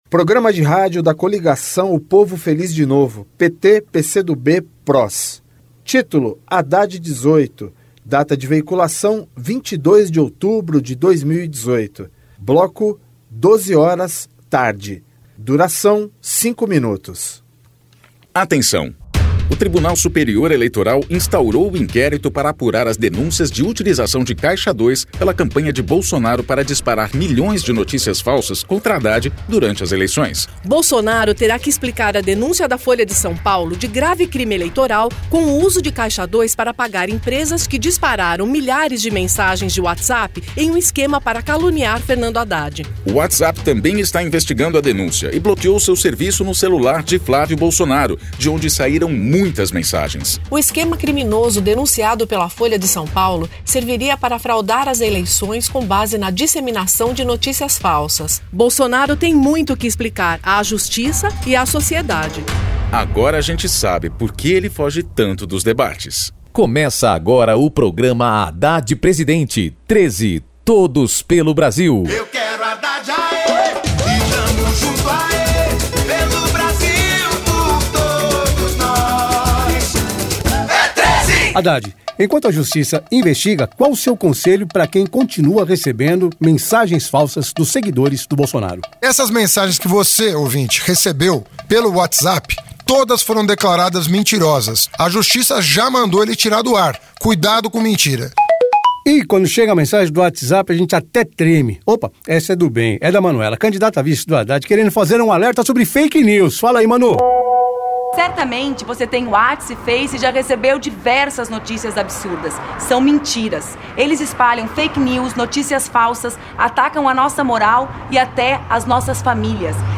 TítuloPrograma de rádio da campanha de 2018 (edição 48)
Gênero documentaldocumento sonoro
Descrição Programa de rádio da campanha de 2018 (edição 48), 2º Turno, 22/10/2018, bloco 12hrs.